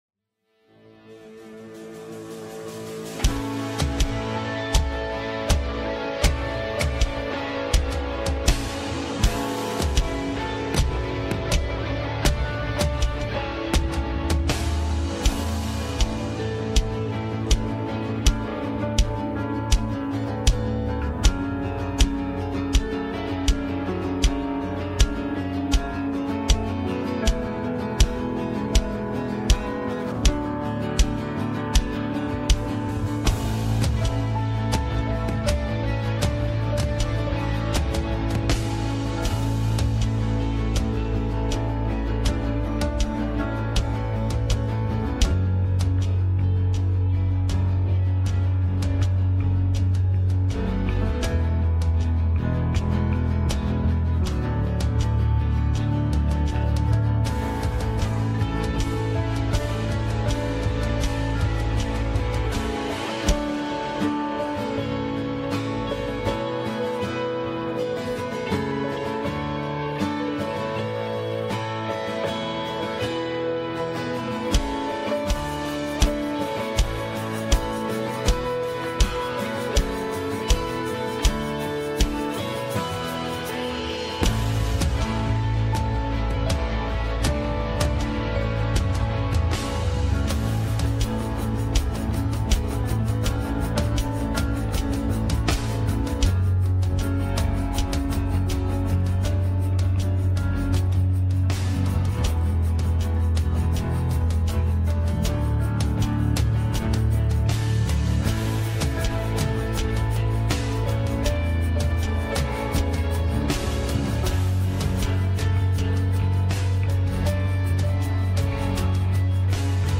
Westgate Chapel Sermons C&MA DNA: Christ Our Savior May 18 2025 | 01:31:02 1x 00:00 / 01:31:02 Subscribe Share Apple Podcasts Overcast RSS Feed Share Link Embed